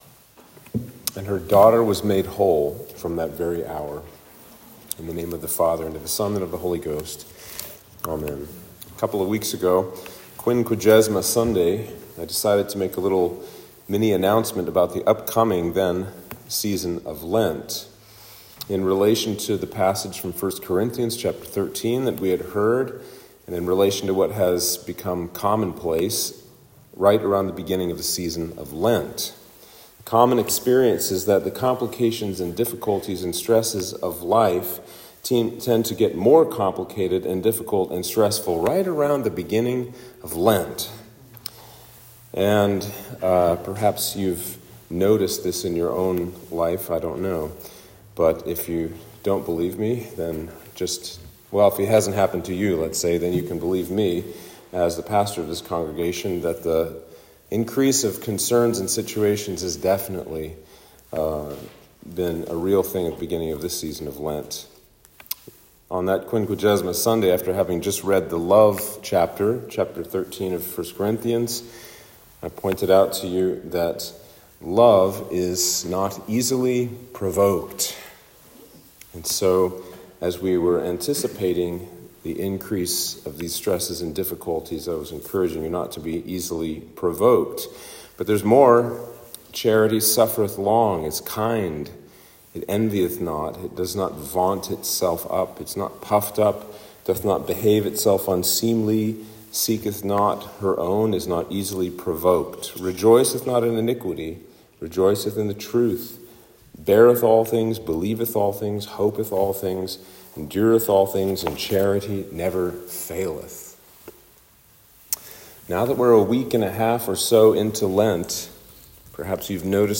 Sermon for Lent 2